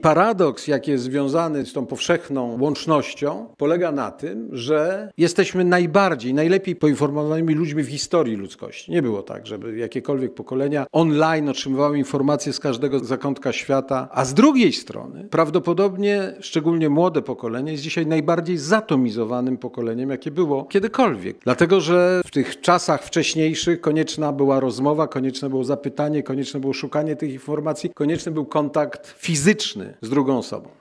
Wyzwaniom współczesnego świata poświęcony jest wykład byłego prezydenta Polski Aleksandra Kwaśniewskiego na Katolickim Uniwersytecie Lubelskim.